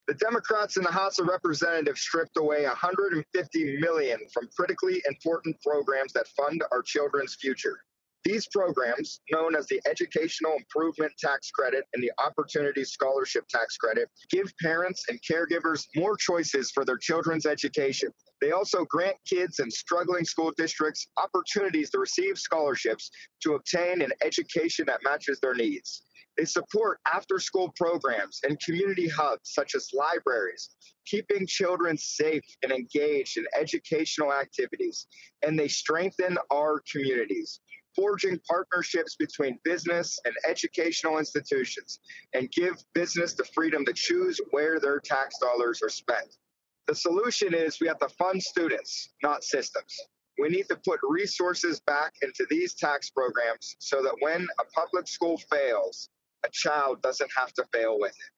(Harrisburg, Pa.) Pennsylvania House Representative and Chairman of the Republican Policy Committee Joshua Kail (R-Beaver/Washington) raised awareness  today that the Democratic Party in the State House have stripped away 150 Million dollars from the Educational Improvement Tax Credit (EITC) and the Opportunity Scholarship Tax Credit (OSTC) programs that fund our Children’s future  Click on the audio link below to listen to Rep. Kail highlight how the programs meet the needs of families and students across the entire income spectrum.